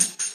Closed Hats
TAMB_HATS2.wav